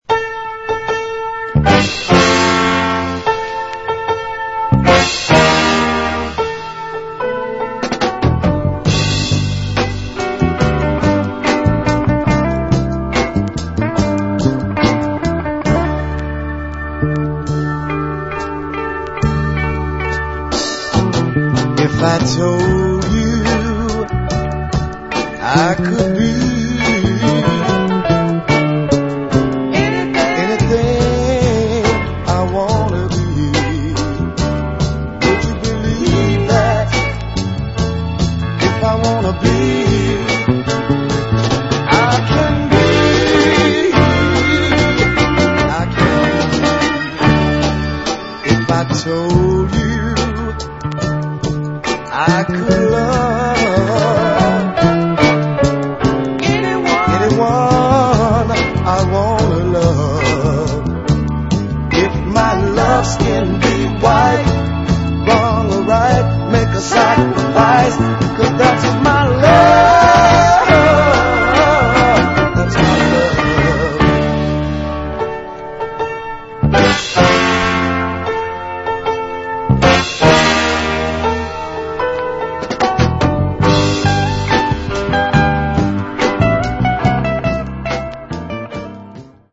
(Vocal & Instrumental)
Really nice group Northern soul.